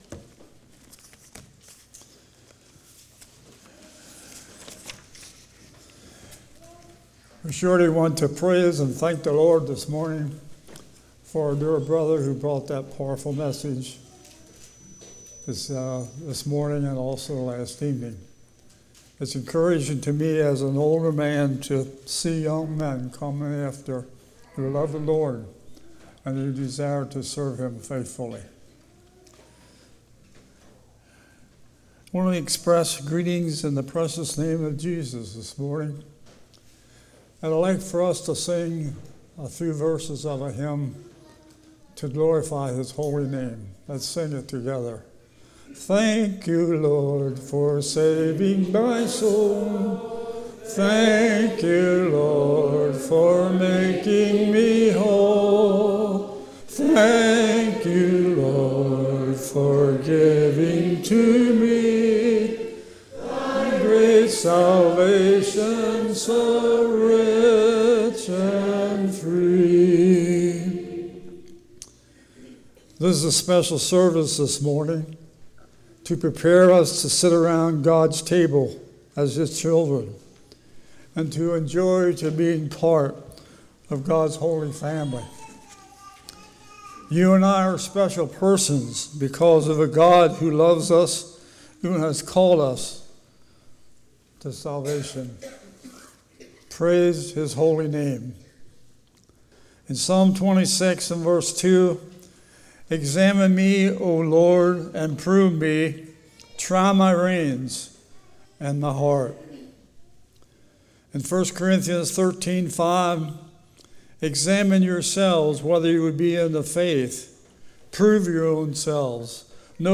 Series: Spring Lovefeast 2018
1 Corinthians 11 Service Type: Morning Are We a Christian?